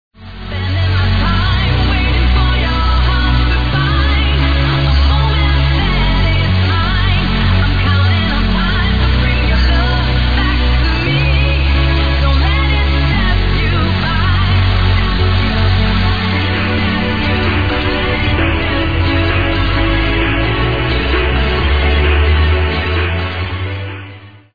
another tune with female vocals